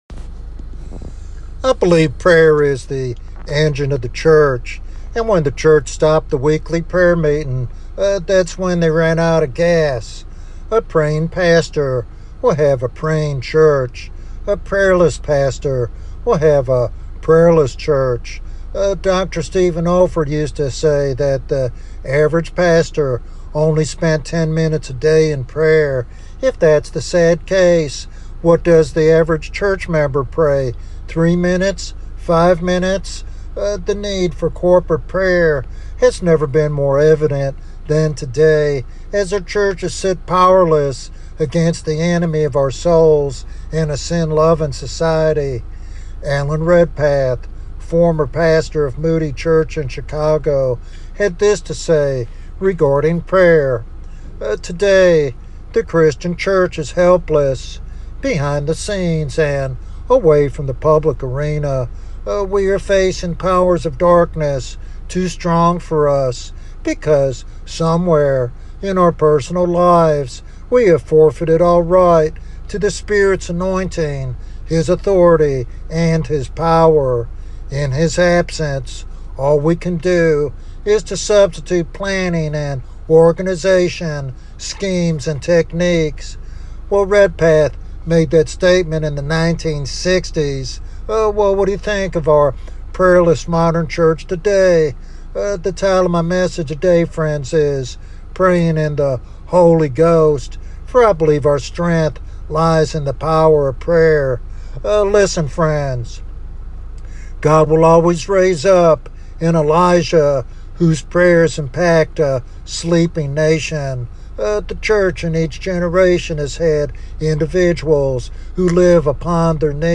This sermon encourages a deeper, more fervent prayer life that aligns with God's power and purposes.
Sermon Outline